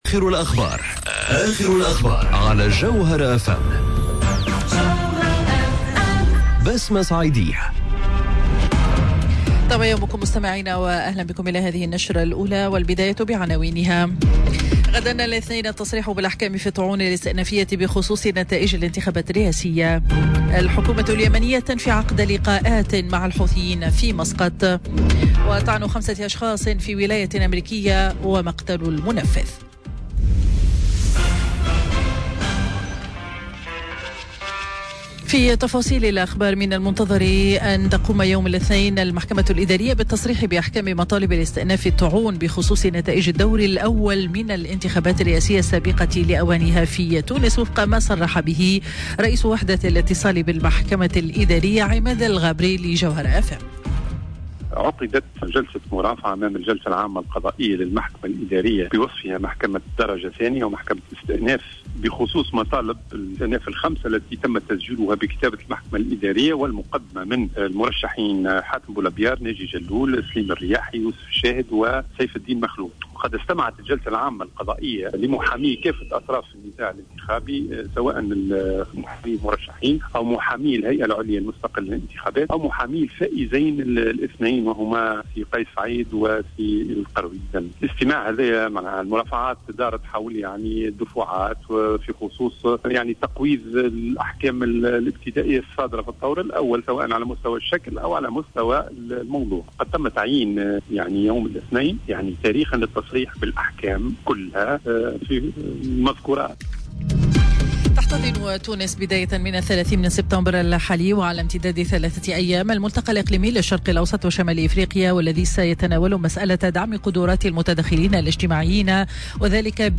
Journal info 07h00 de dimanche 29 Septembre 2019